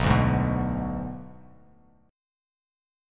Bm6 Chord
Listen to Bm6 strummed